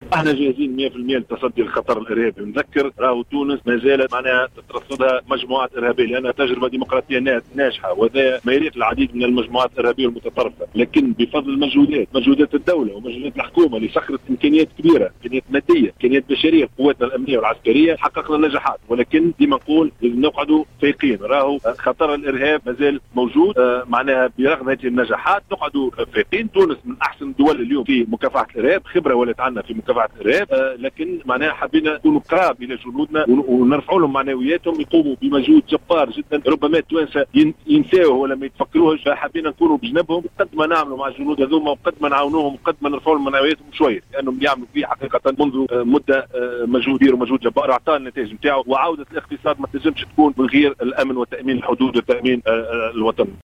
وجاءت تصريحاته على هامش زيارة تفقّدية قام بها اليوم الثلاثاء الى ثكنة رمادة العسكرية في تطاوين، أكد فيها أن تونس أصبح لديها خبرة في التصدي للارهاب.